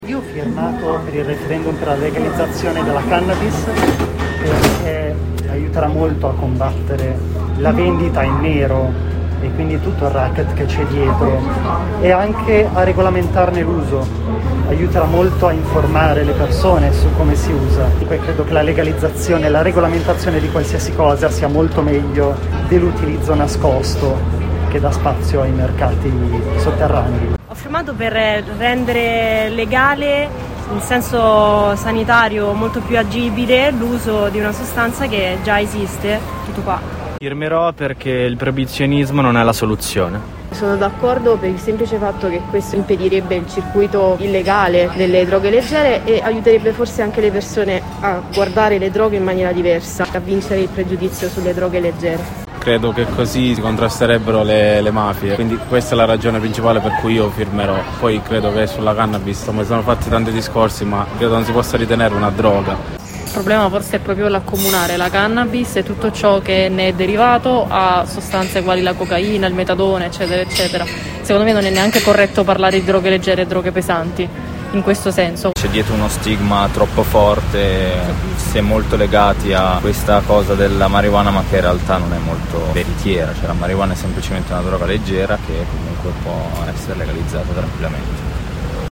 VOCI_REFERENDUM-CANNABIS_19.mp3